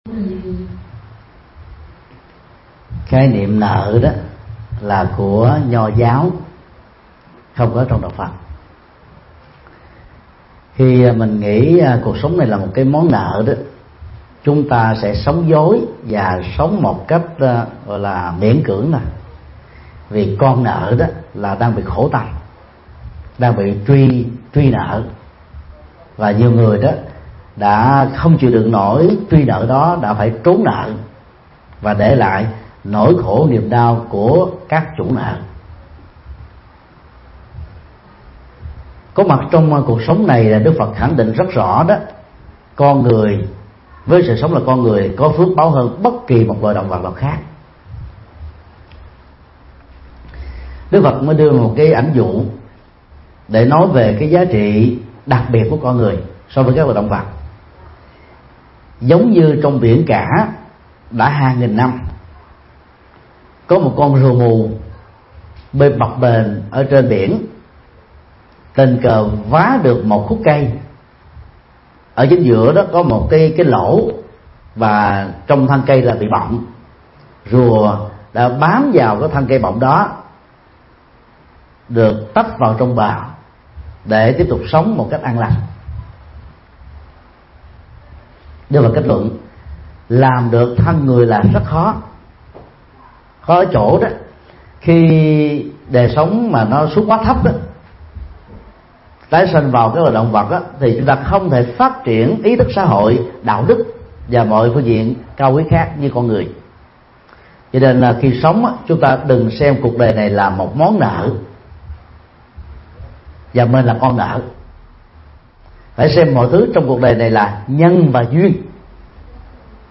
Vấn đáp: Khái niệm duyên nợ – Thích Nhật Từ